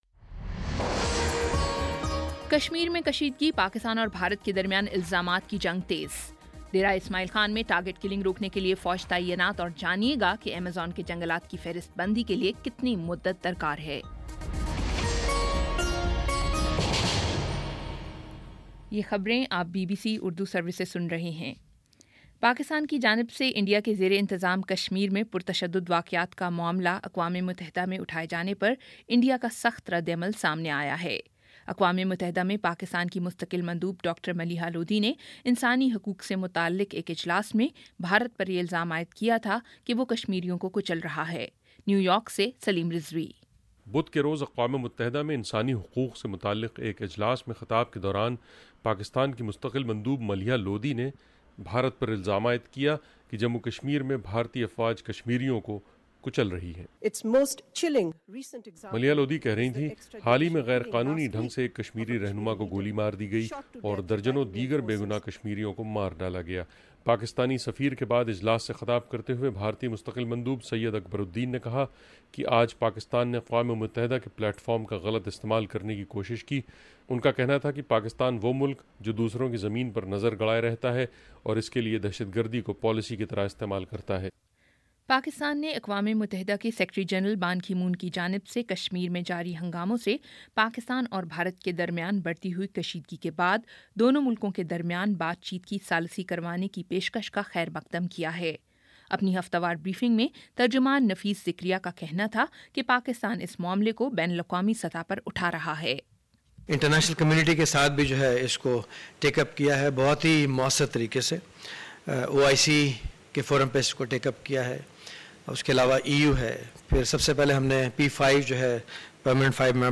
جولائی 14 : شام چھ بجے کا نیوز بُلیٹن